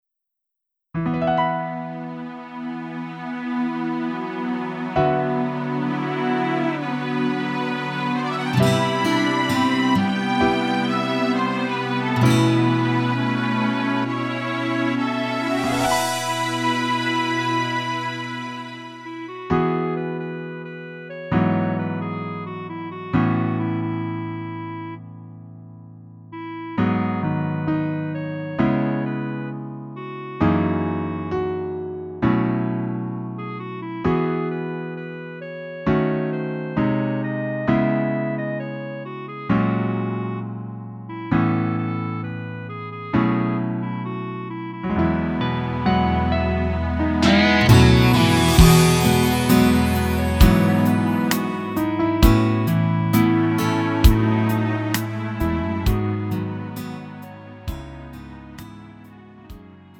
음정 -1키 4:13
장르 구분 Lite MR